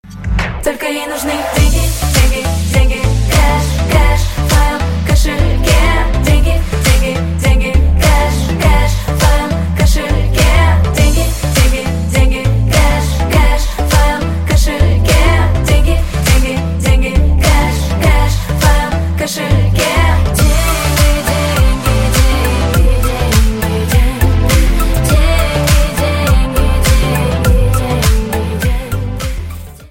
женский вокал
dance
club
чувственные